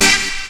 REGGAE HIT.wav